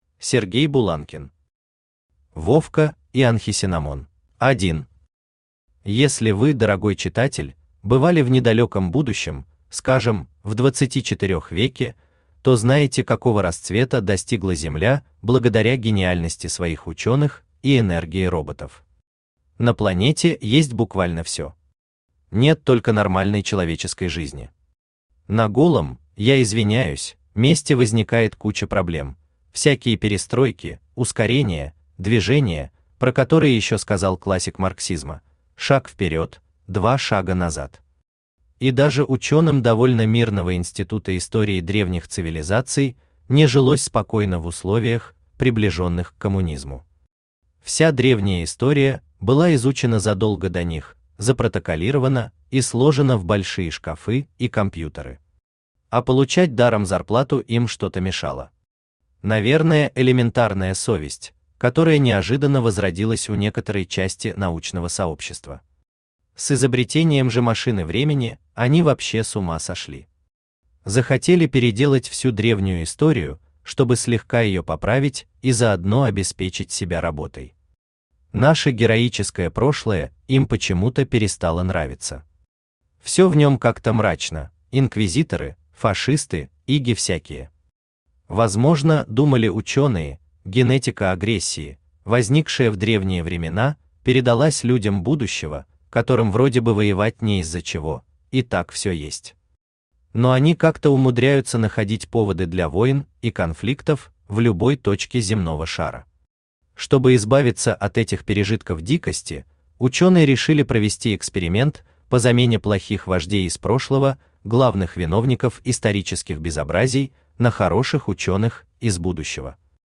Аудиокнига Вовка и Анхесенамон | Библиотека аудиокниг
Aудиокнига Вовка и Анхесенамон Автор Сергей Васильевич Буланкин Читает аудиокнигу Авточтец ЛитРес.